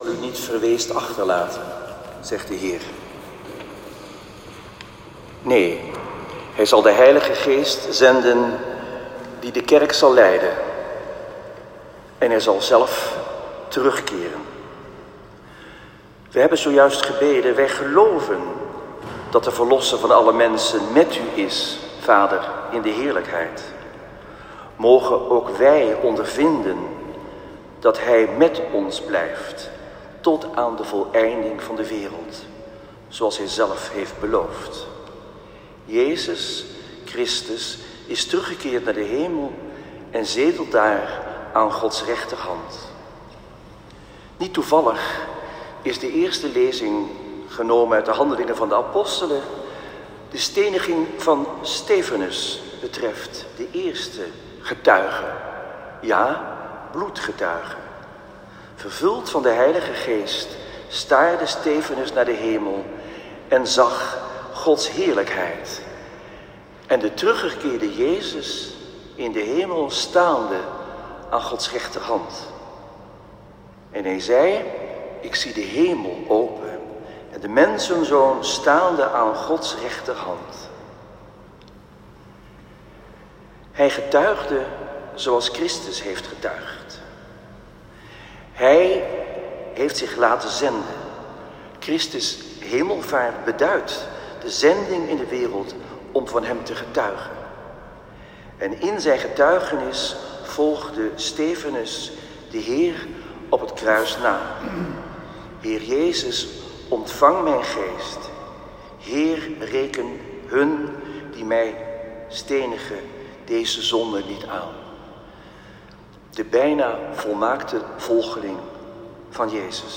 Zevende zondag van Pasen. Celebrant Antoine Bodar.
Preek-3.m4a